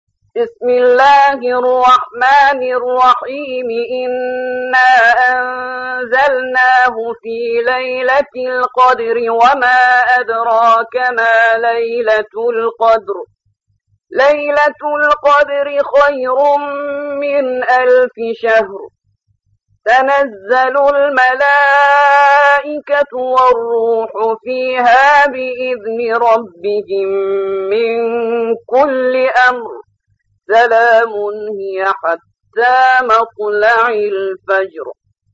97. سورة القدر / القارئ